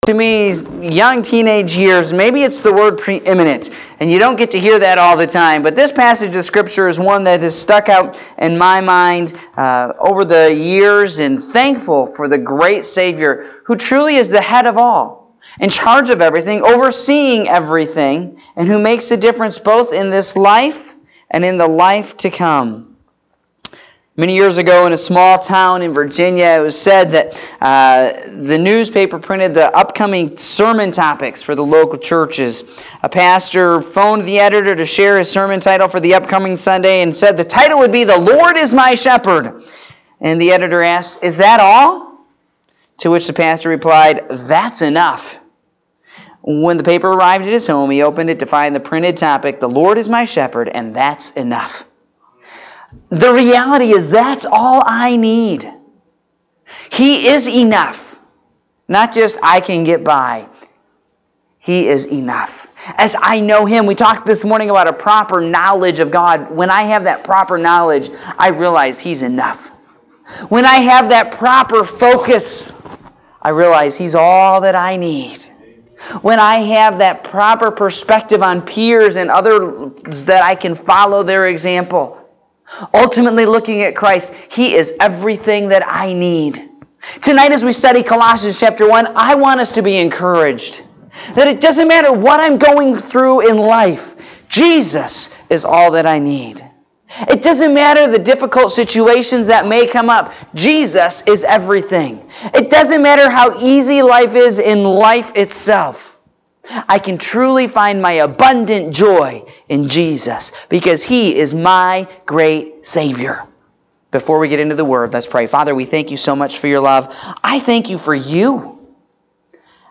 Evening Service (3/11/2018)